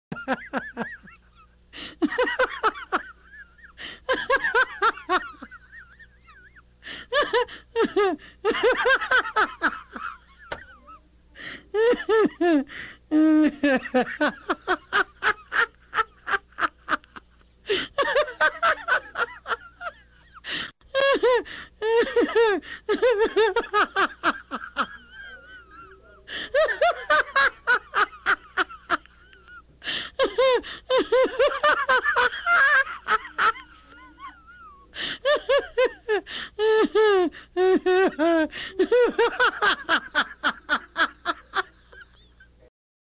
Risadas
risadas.mp3